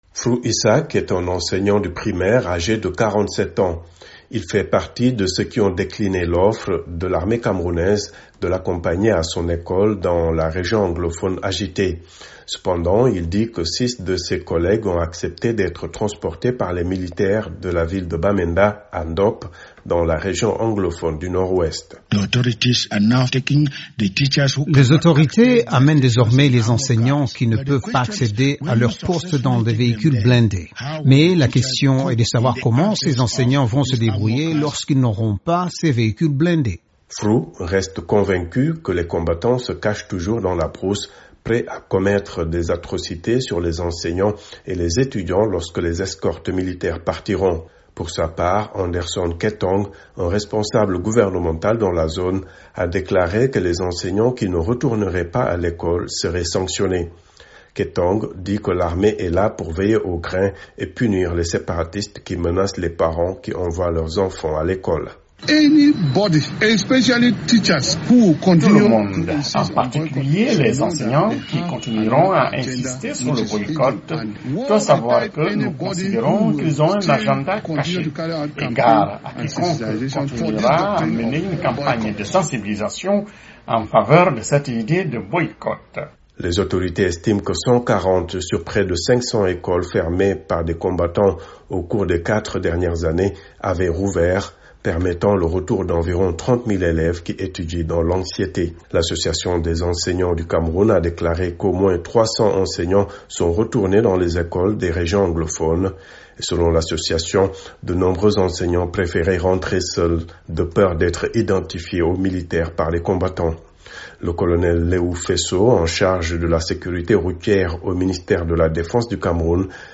Les enseignants disent craindre pour leur sécurité puisque les séparatistes menacent ceux qui retourneraient dans les salles de classe. Reportage à Yaoundé